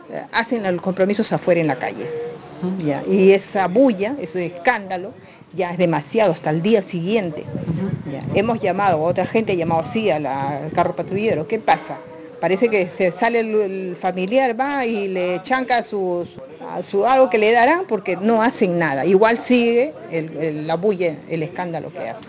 Una pobladora del sector, quien evitó dar su nombre por motivos de seguridad, afirmó que jóvenes provenientes de barrios vecinos frecuentan el parque virgen de las mercedes para consumir estupefacientes en plena vía pública.
AUDIO-02-POBLADORA.mp3